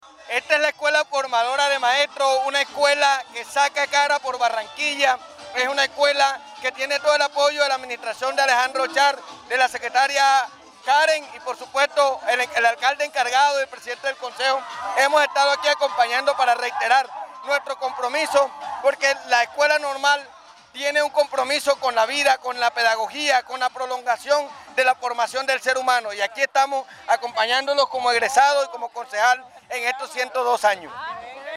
El presidente del Concejo de Barranquilla, Juan Carlos Ospino explicó que esa corporación distrital hace el acompañamiento en una de las mejores instituciones de la ciudad y el país. Manifestó que las condiciones están dadas para que se siga invirtiendo en los colegios de Barranquilla.